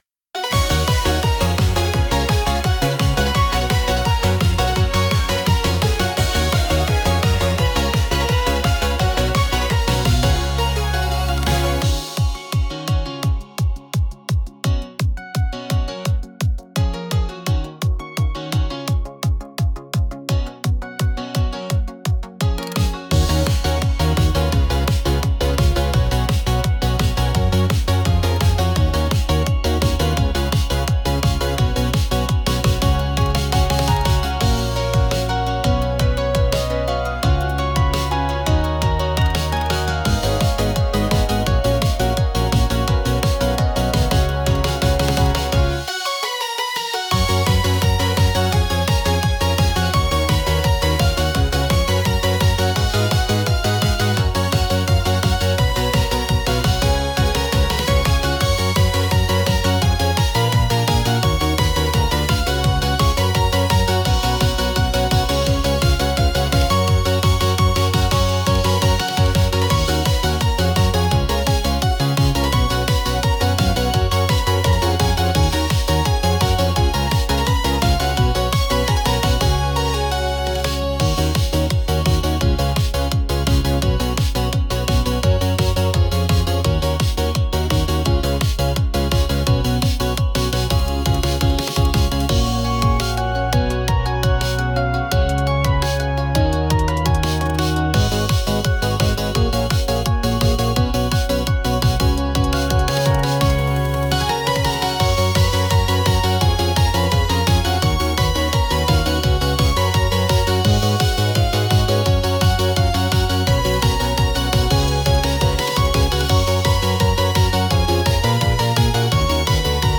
- フリーBGM 🎶 キラキラと輝くステージのような、アイドル風ポップインストBGMです。